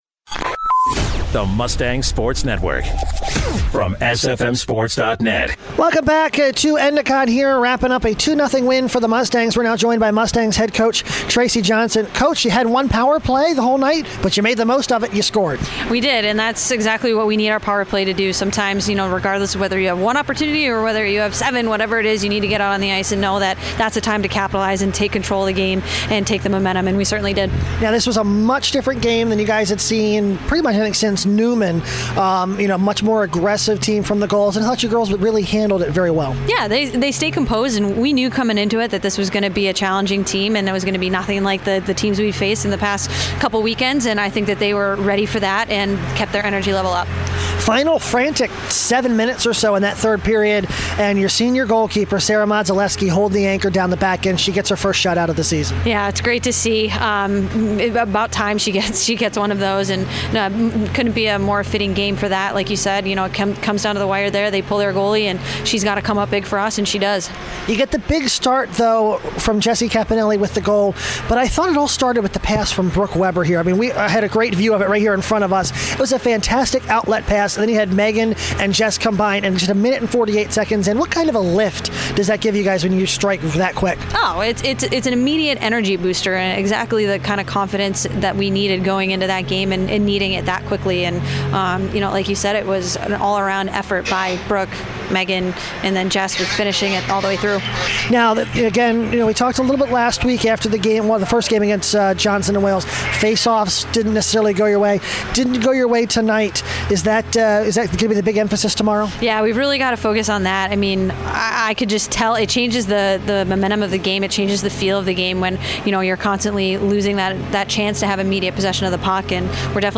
11/20/15: Stevenson Ice Hockey Post Game Show